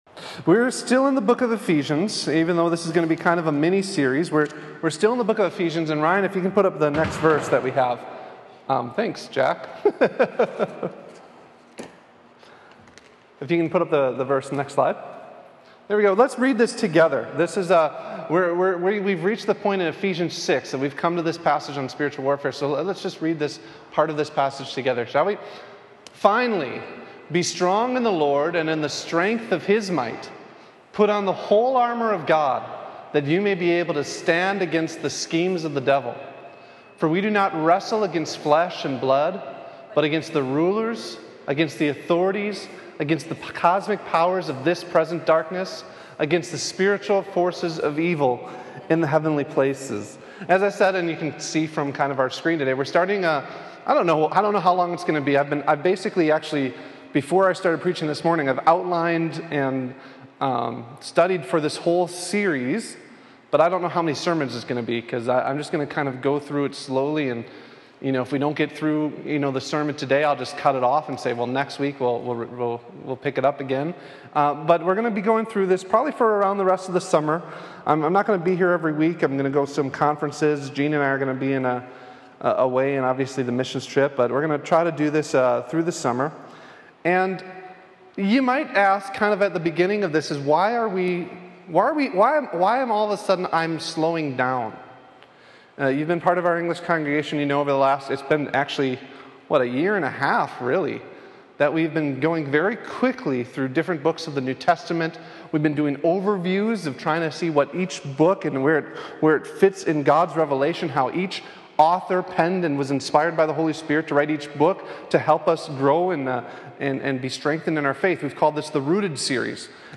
Sermon #1: The reality of our warfare: